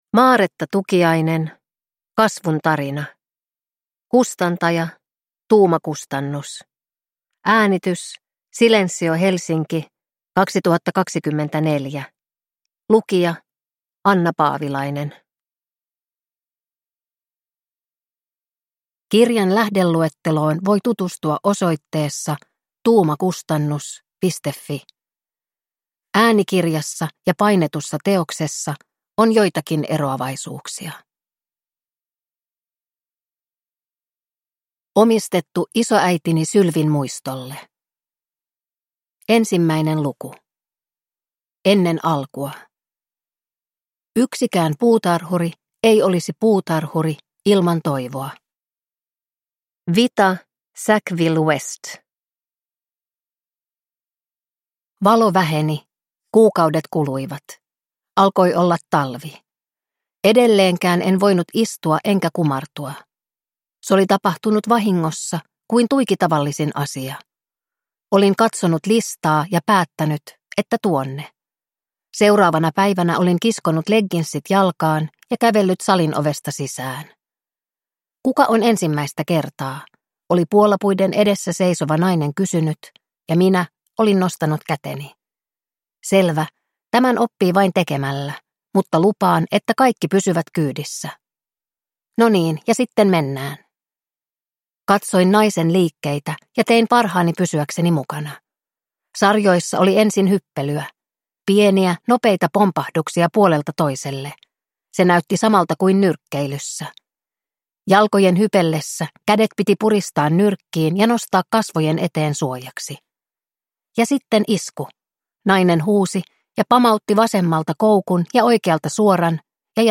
Kasvun tarina – Ljudbok